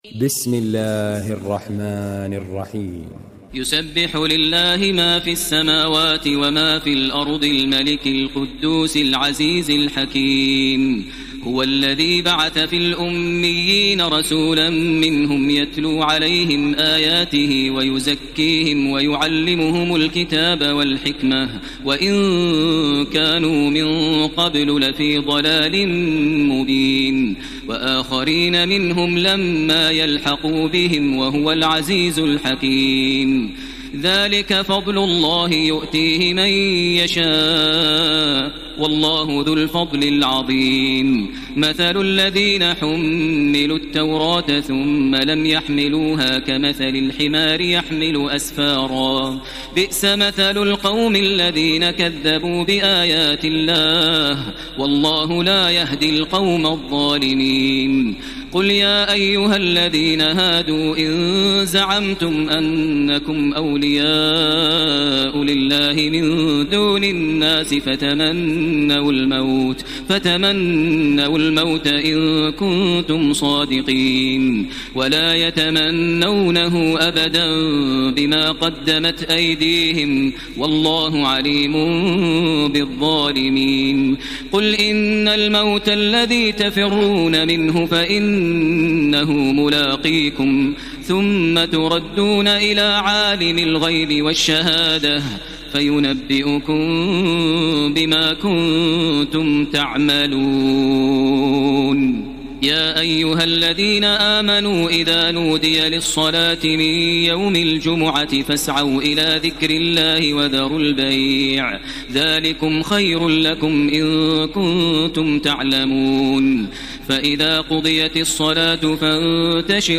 تراويح ليلة 27 رمضان 1433هـ من سورة الجمعة الى التحريم Taraweeh 27 st night Ramadan 1433H from Surah Al-Jumu'a to At-Tahrim > تراويح الحرم المكي عام 1433 🕋 > التراويح - تلاوات الحرمين